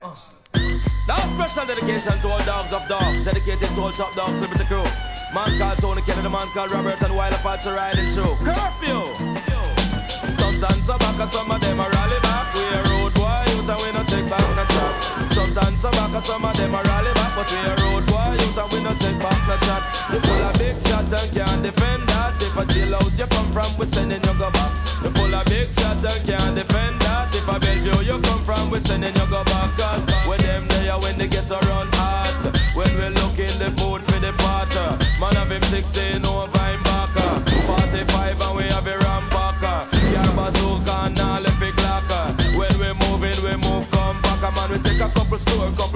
REGGAE
ラガ HIP HOP!!